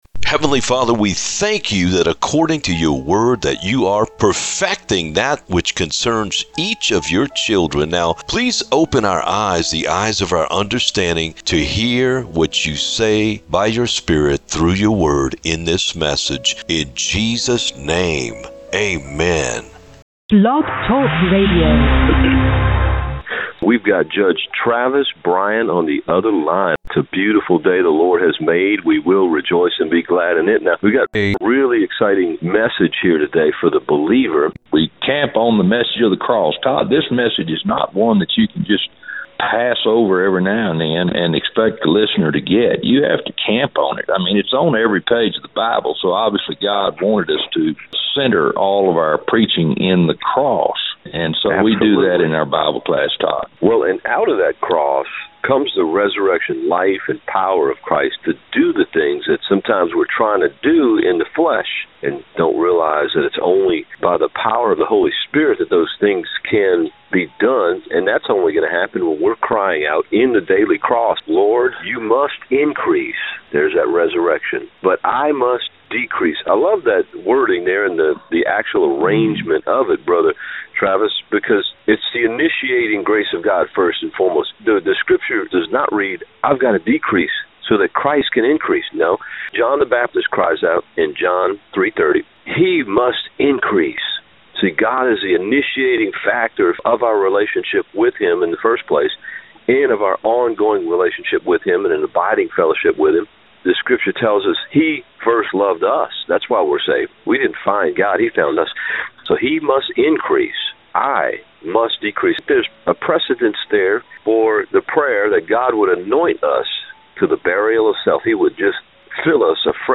UNplugged Interview with Judge Travis Bryan III [podcast] - SafeGuardYourSoul
UNplugged-Interview-with-Judge-Travis-Bryan-III-EDITED-MUSIC.mp3